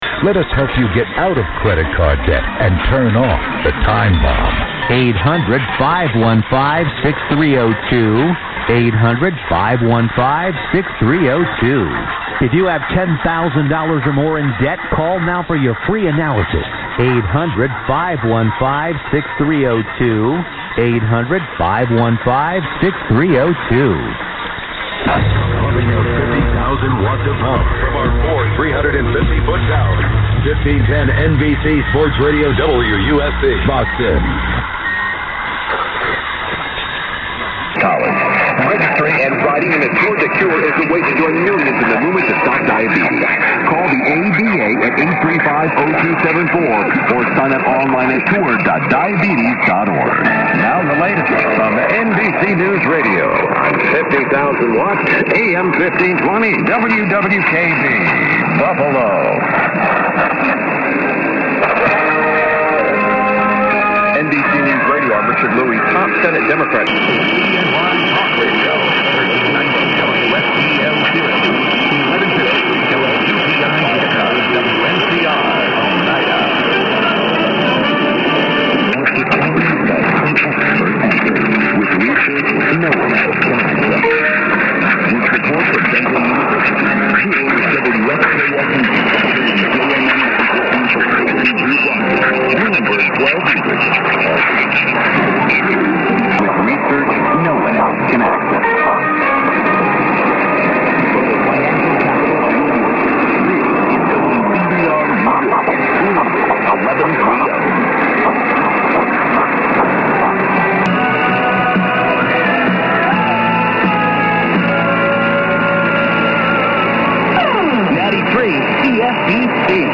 MW DXING:
I am not going to list log the whole band here, but I have made a montage of some of the better signals, from 11th and 10th.
700 WLW V Weak
130410_and_11th_montage.mp3